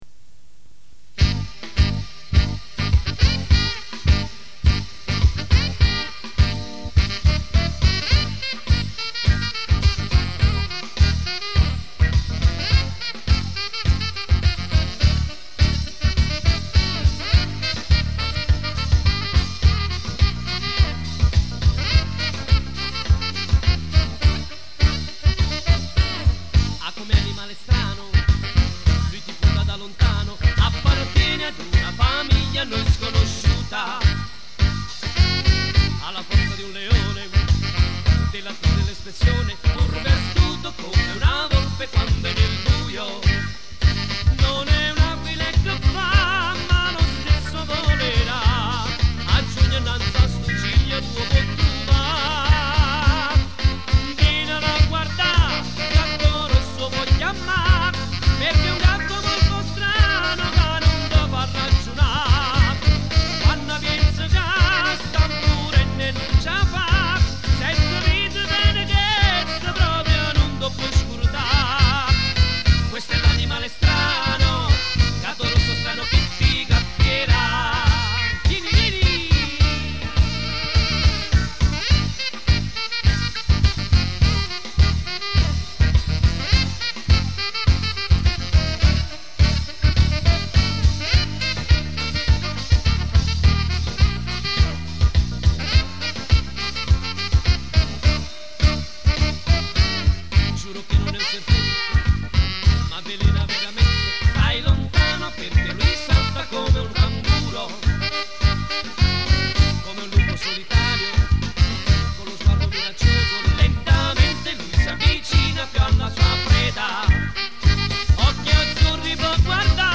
versione live